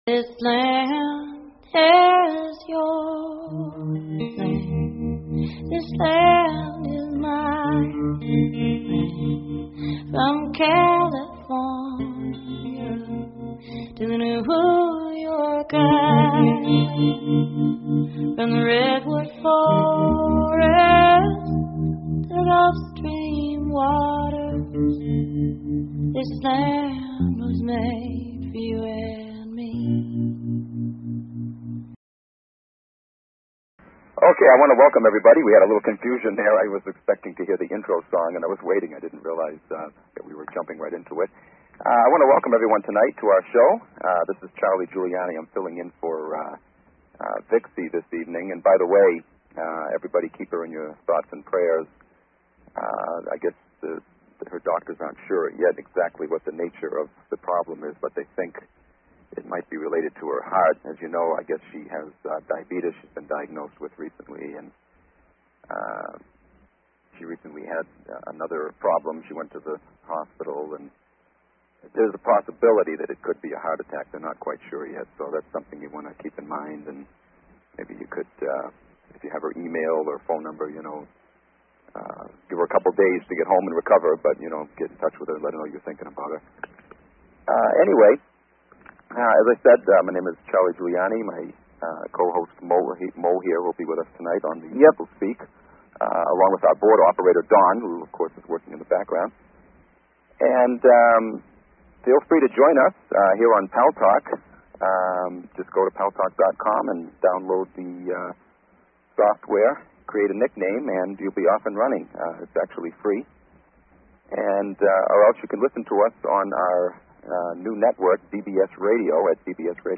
Guest, Immortal Technique